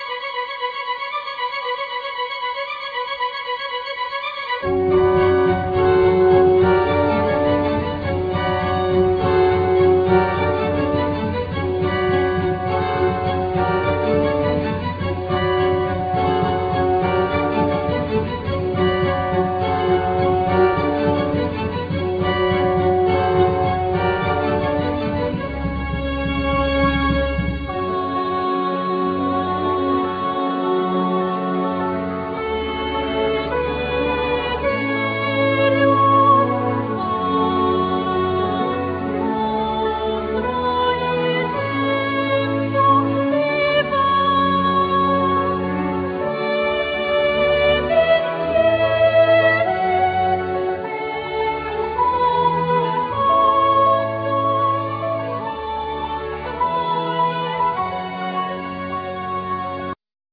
Soprano
Mezzo Soprano
Tennor
Piano
Orchestra